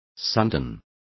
Complete with pronunciation of the translation of suntan.